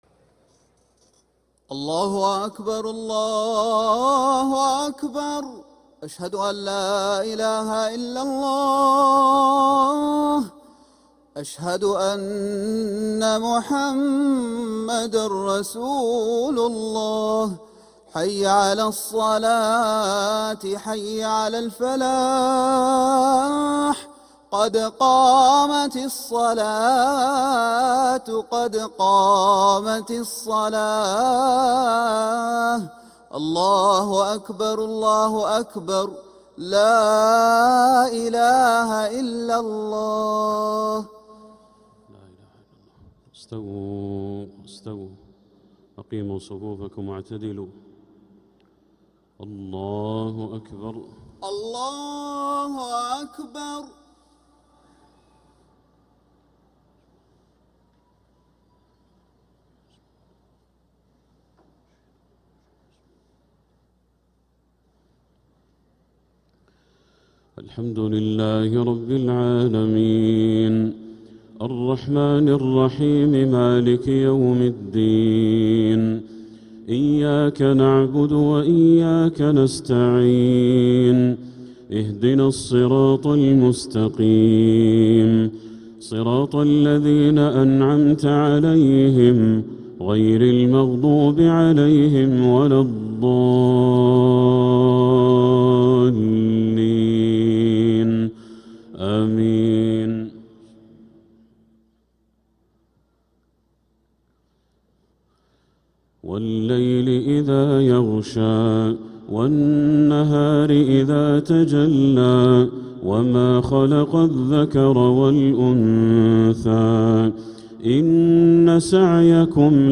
Makkah Isha - 07th February 2026